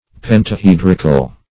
Pentahedrical \Pen`ta*hed"ric*al\, a.
pentahedrical.mp3